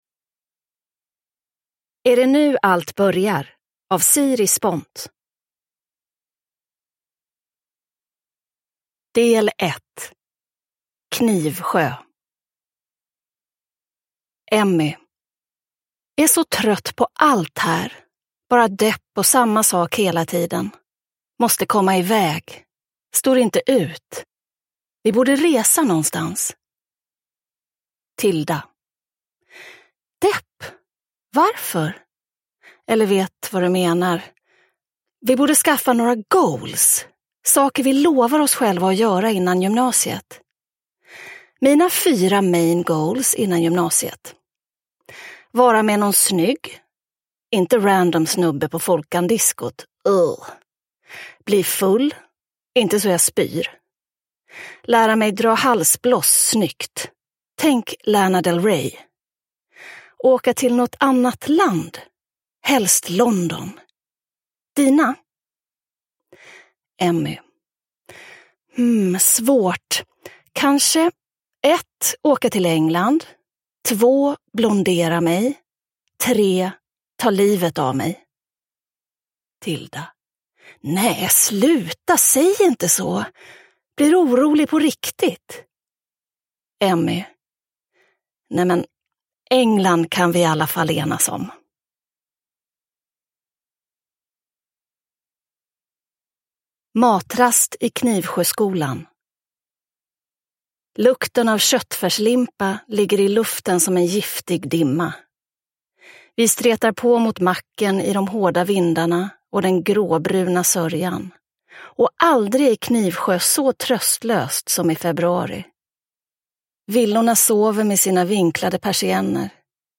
Är det nu allt börjar? – Ljudbok – Laddas ner